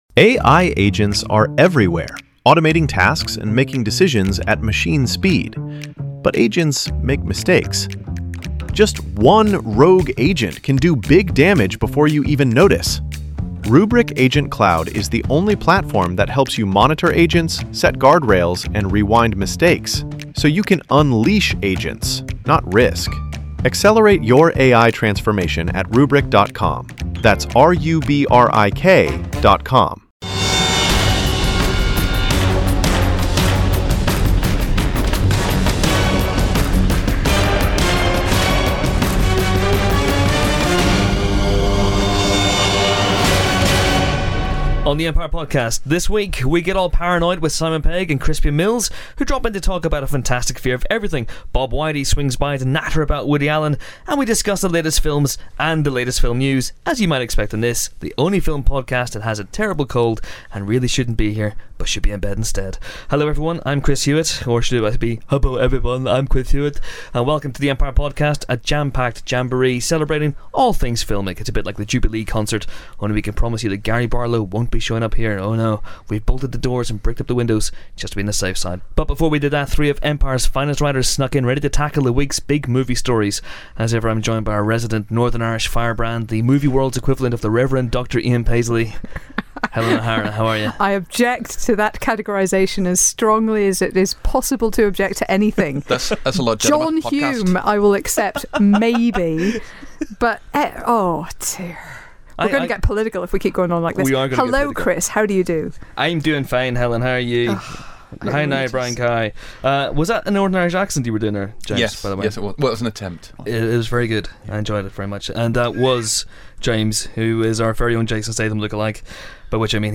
The fifteenth Empire podcast boasts not one, not two, but three interviewees, namely the director and star of Fantastic Fear Of Everything - Crispian Mills and Simon Pegg - and the man behind Woody Allen: A Documentary, Robert Weide. Pegg tells us a little bit about Star Trek 2, The World's End and Nick Frost's dance moves, while Weide delivers an Allen impersonation that'll knock your socks off.